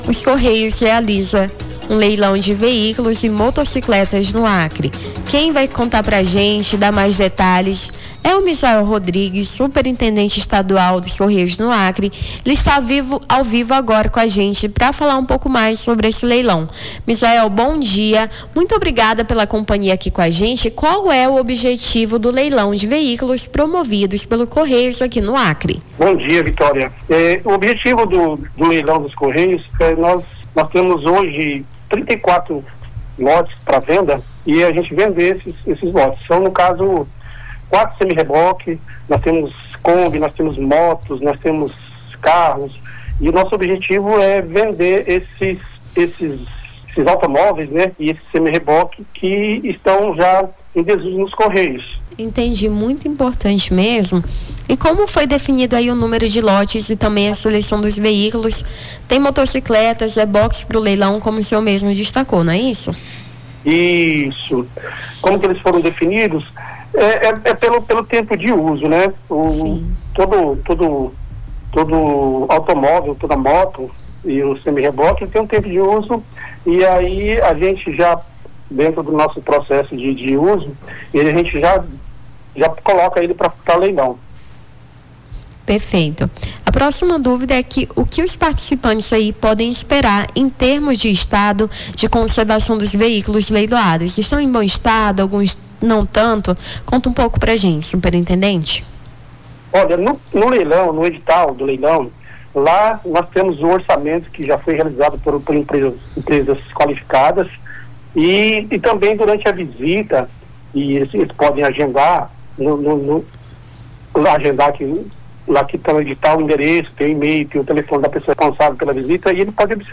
Nome do Artista - CENSURA - ENTREVISTA LEILÃO CORREIOS (30-05-25).mp3